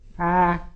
NURSE can be heard more open and back than [əː]; I tend to associate this with younger, relatively posh females. Listen to her said by Emma Watson: